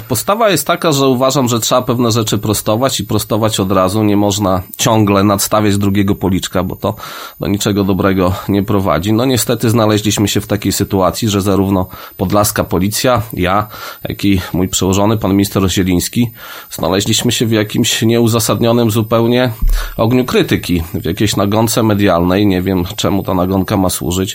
Do anonimów i wyników kontroli w podlaskiej policji odniósł się w poniedziałek (26.11) na antenie Radia 5 nadinspektor Daniel Kołnierowicz, Komendant Wojewódzki Policji w Białymstoku.